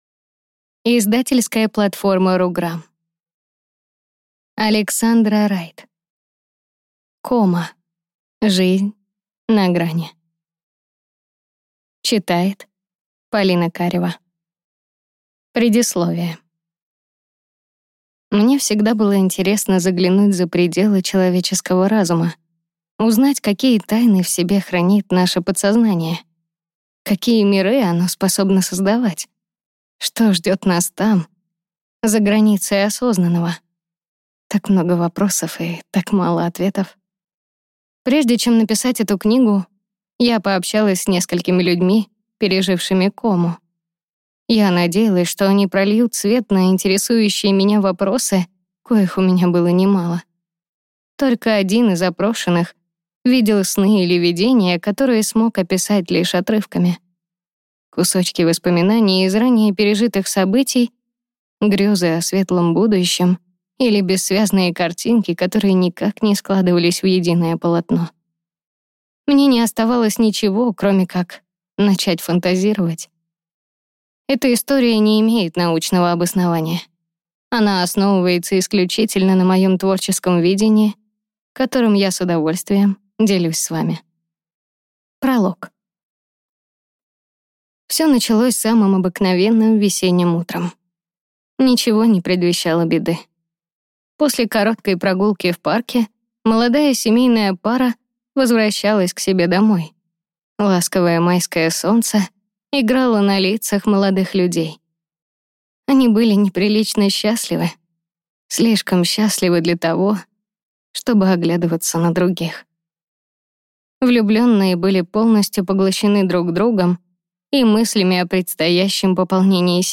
Аудиокнига Кома. Жизнь на грани | Библиотека аудиокниг